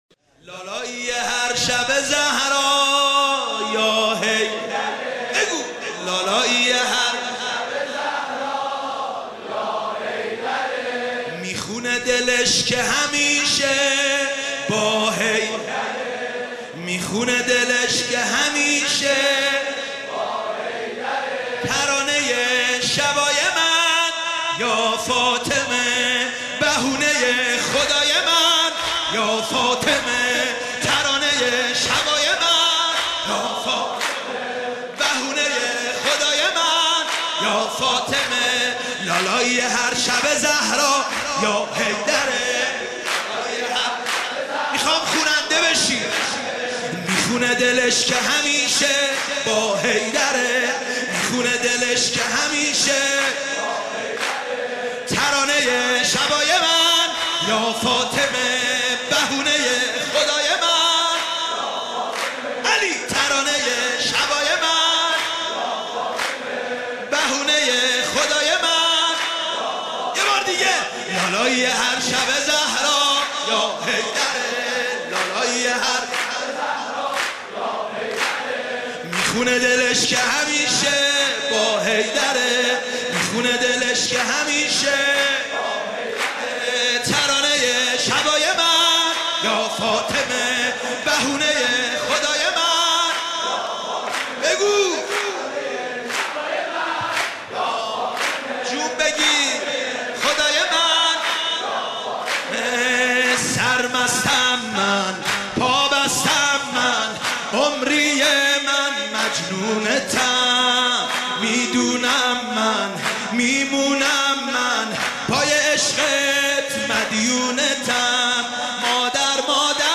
بمناسبت میلاد با سعادت حضرت زهرا سلام الله علیها.
مولودی خوانی